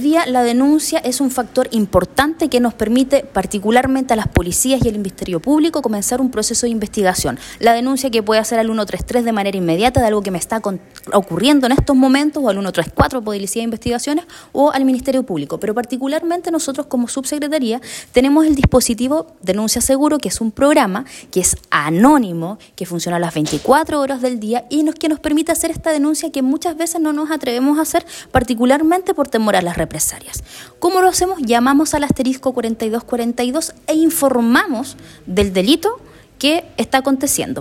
Paulina Muñoz, ex representante de la Subsecretaría de Prevención del Delito y actual Delegada Presidencial Regional, destacó la importancia de este sistema en la construcción de comunidades más seguras.